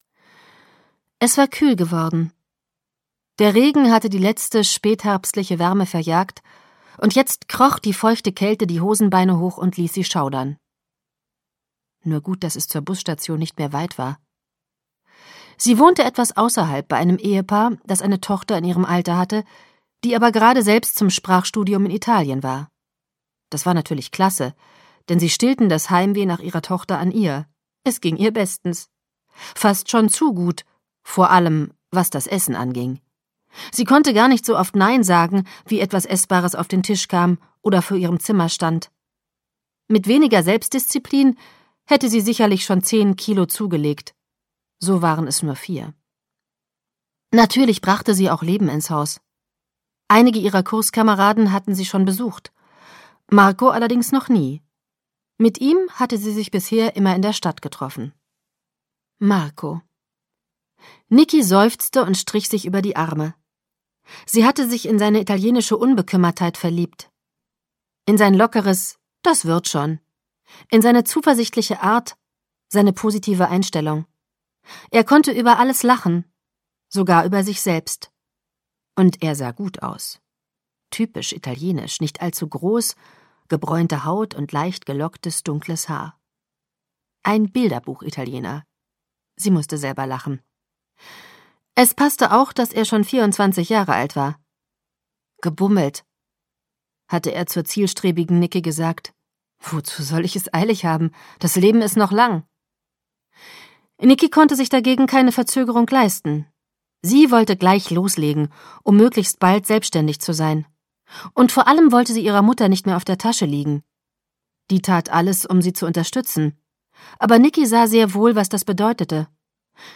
Hörbuch Nicht schon wieder al dente, Gaby Hauptmann.